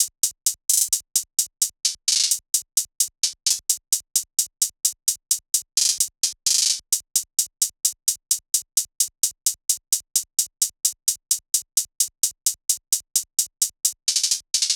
SOUTHSIDE_beat_loop_grey_hihat_130.wav